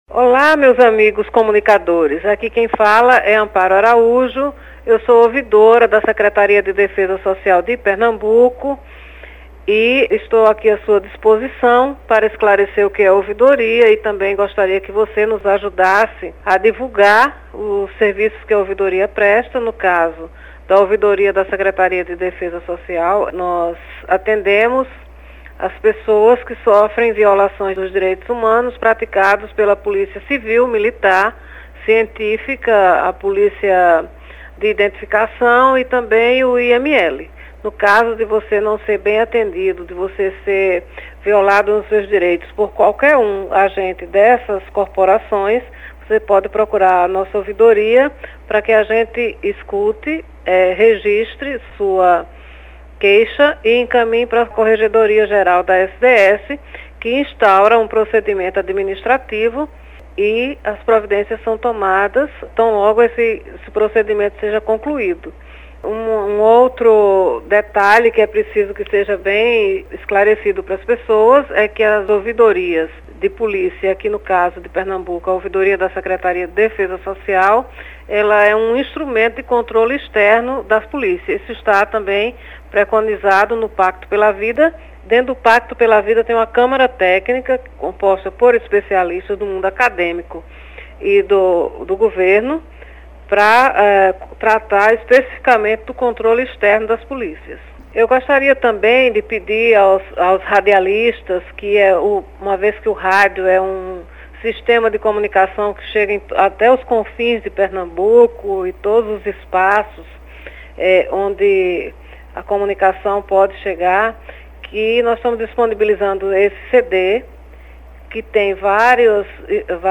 Faixa 3 - Carta Falada de Amparo Araújo, Ouvidora da Secretaria de Defesa Social - PE (5:15)